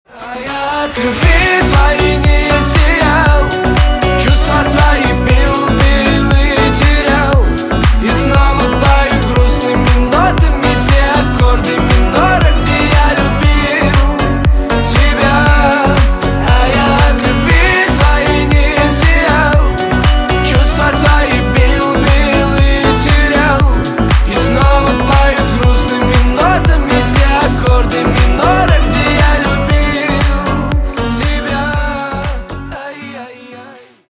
восточные на бывшую на русском про любовь грустные